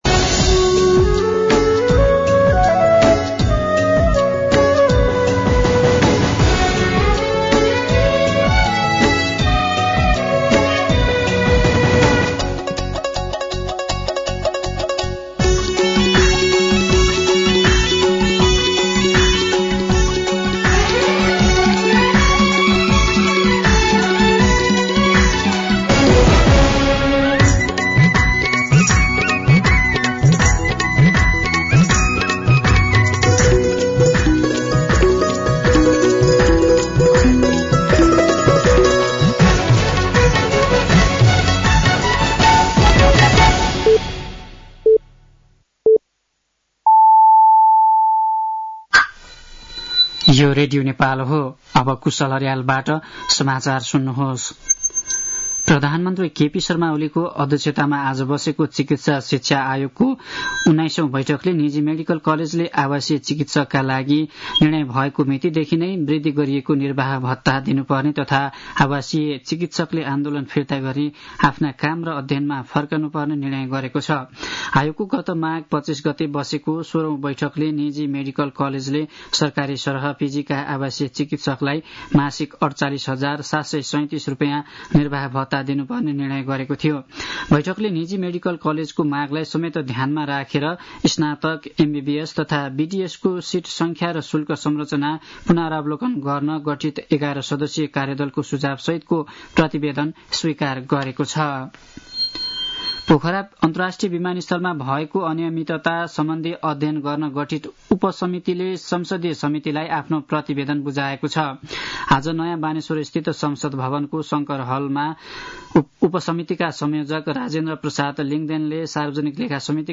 साँझ ५ बजेको नेपाली समाचार : १५ वैशाख , २०८२
5.-pm-nepali-news-5.mp3